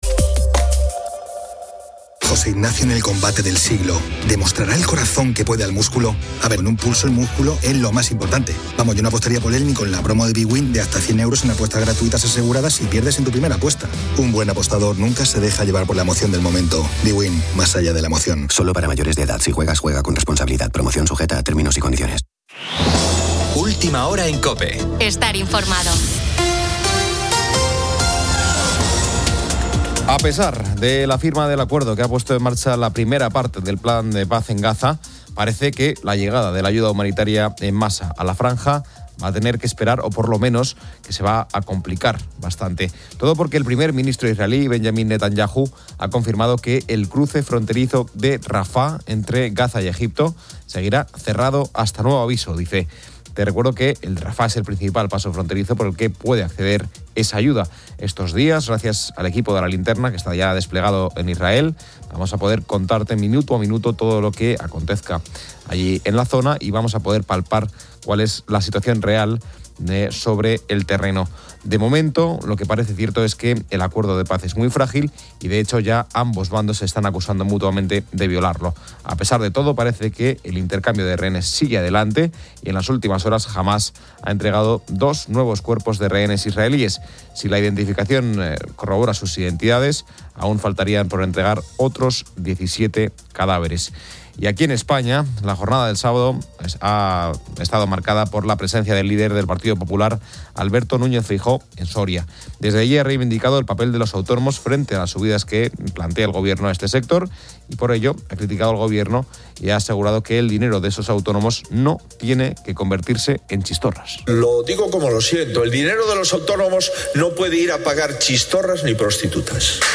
un monólogo sobre temas variados. Se debate sobre artistas y hermanos a la sombra en la música y el cine.